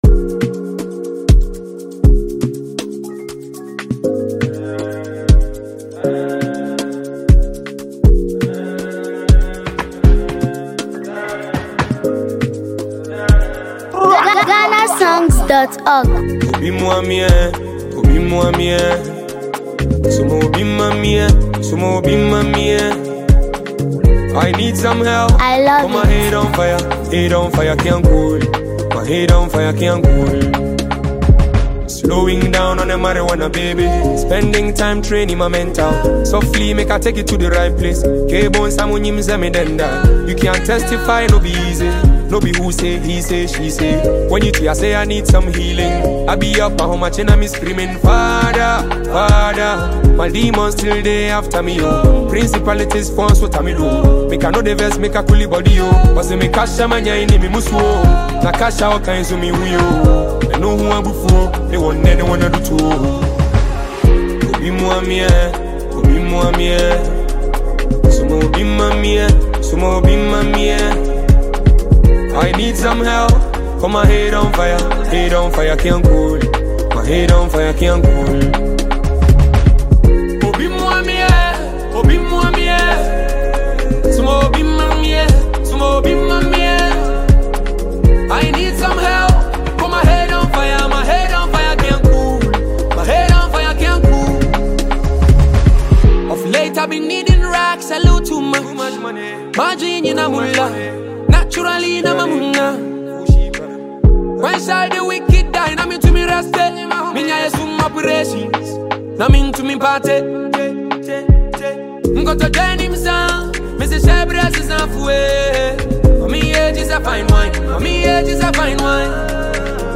Genre: Afrobeat / Highlife / Drill